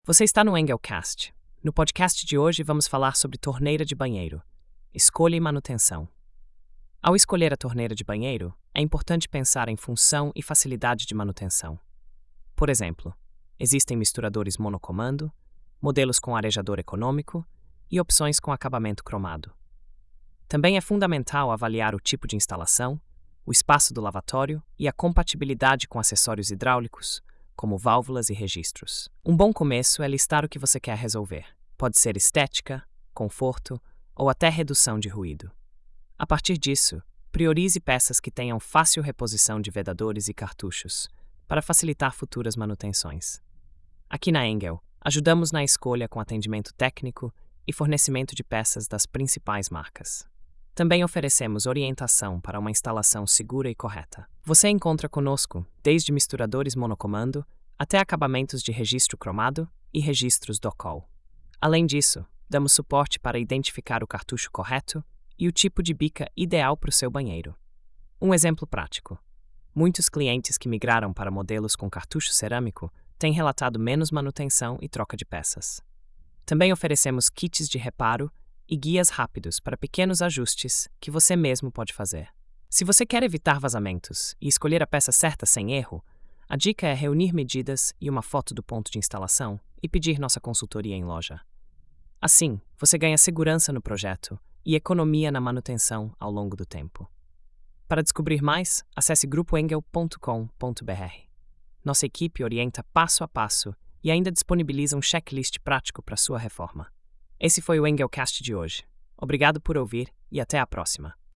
Narração automática por IA • Construção & Reformas